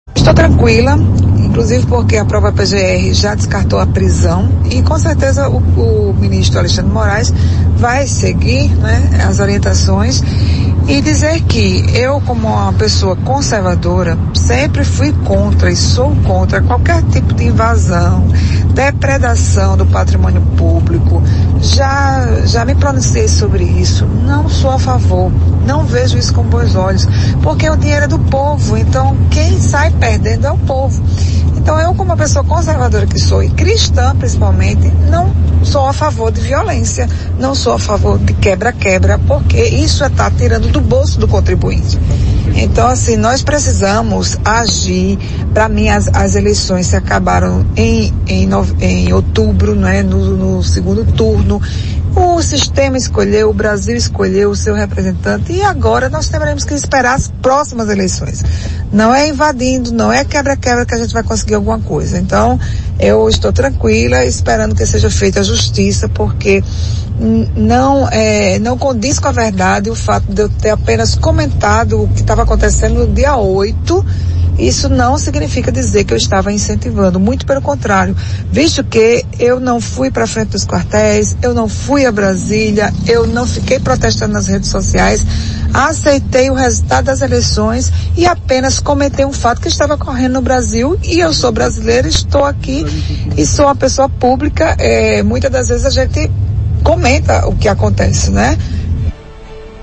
As declarações repercutiram no programa Arapuan Verdade.